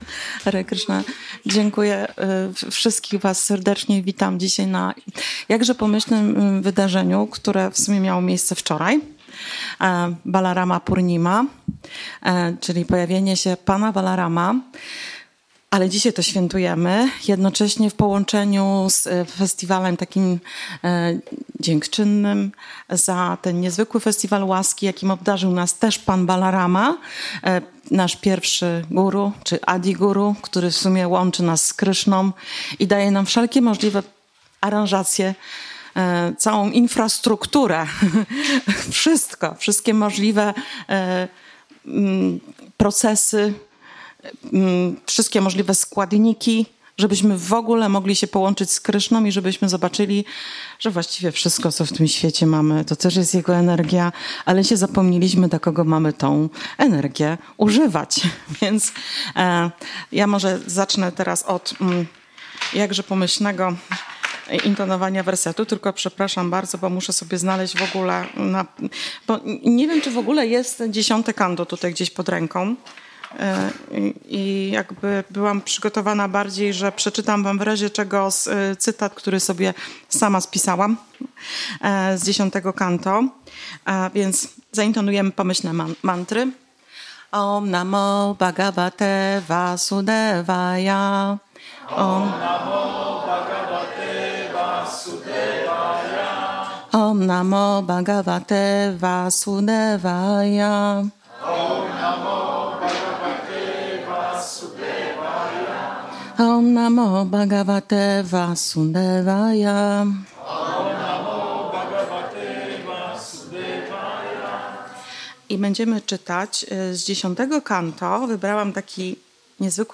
Wykład wygłoszony z okazji święta Balarama Purnima 10 sierpnia 2025 roku. W nagraniu jest przerwa spowodowana brakiem prądu.